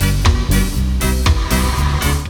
DUBLOOP 07-R.wav